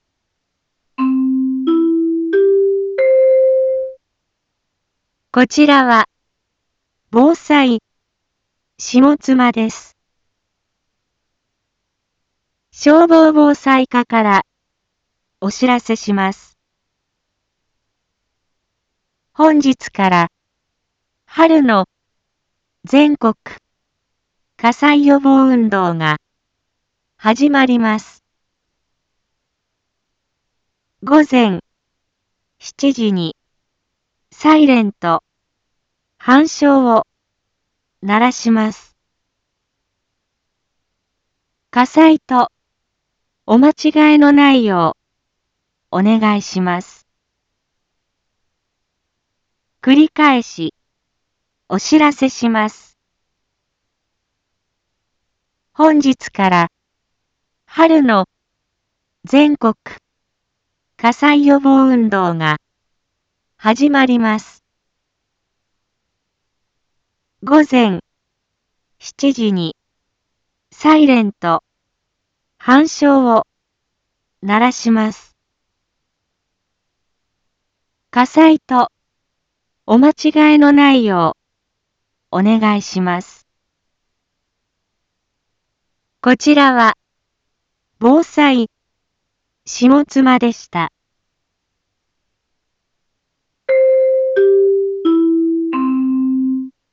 一般放送情報
Back Home 一般放送情報 音声放送 再生 一般放送情報 登録日時：2026-03-01 06:46:57 タイトル：春季全国火災予防運動に伴うサイレン吹鳴 インフォメーション：こちらは、ぼうさいしもつまです。